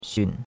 xun3.mp3